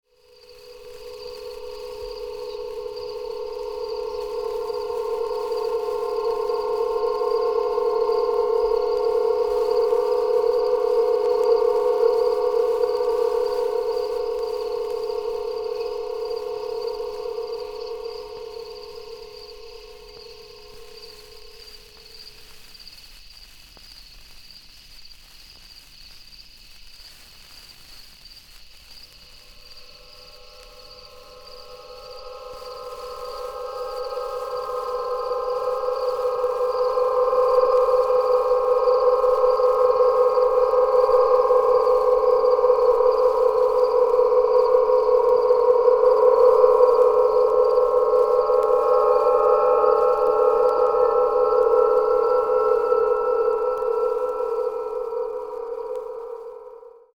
キーワード：音響彫刻　自然音　ドローン